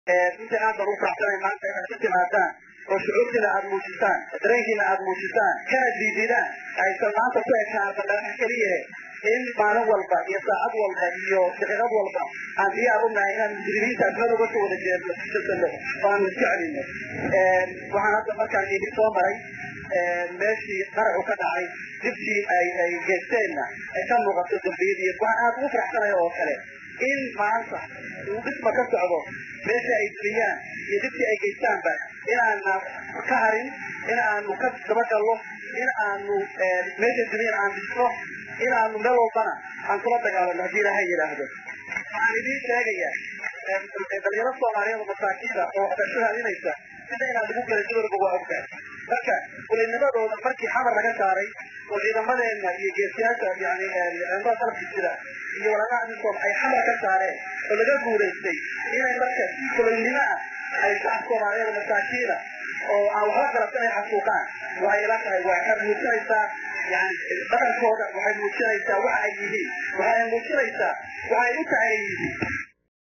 Banaabax loogu magac daray maalinta cadhada ayaa lagu qabtay Garoonka burburay ee Koonis Stadium ee magaalada Muqdisho.